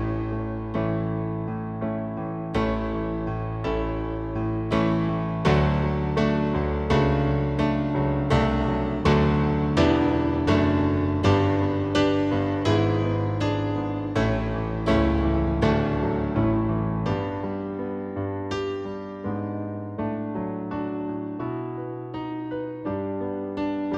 One Semitone Down Pop (1960s) 4:51 Buy £1.50